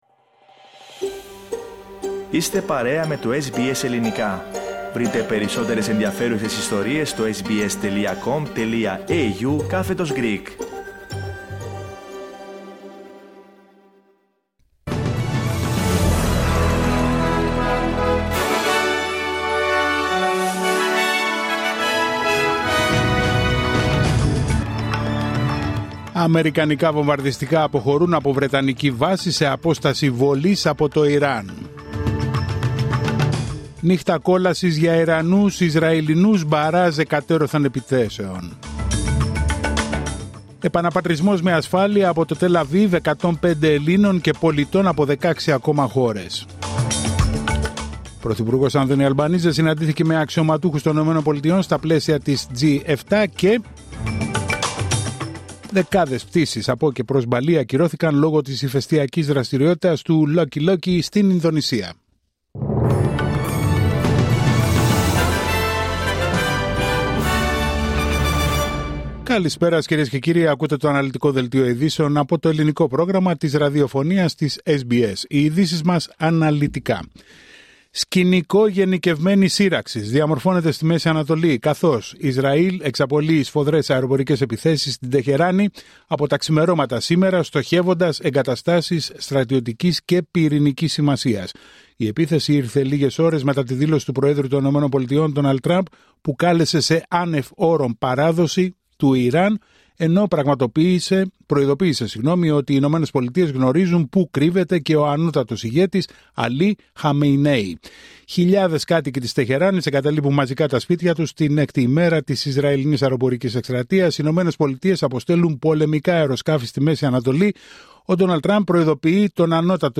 Δελτίο ειδήσεων Τετάρτη 18 Ιουνίου 2025